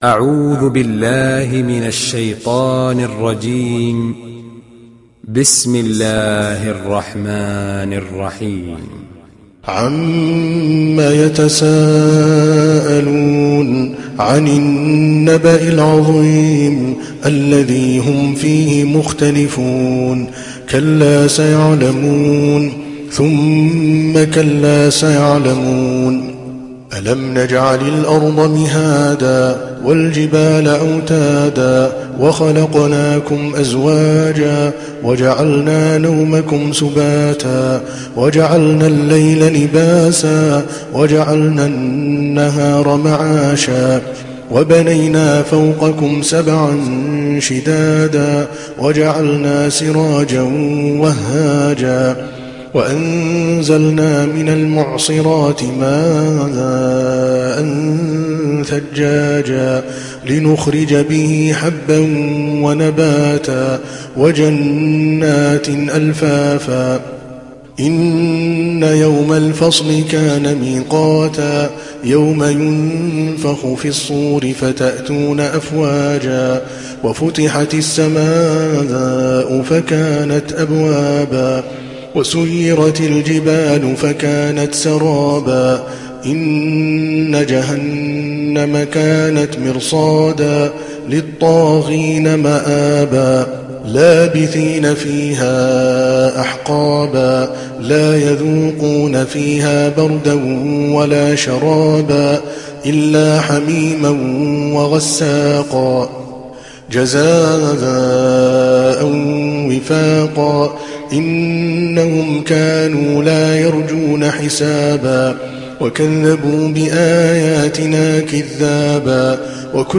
تحميل سورة النبأ mp3 بصوت عادل الكلباني برواية حفص عن عاصم, تحميل استماع القرآن الكريم على الجوال mp3 كاملا بروابط مباشرة وسريعة